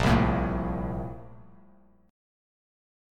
A#M7sus2 chord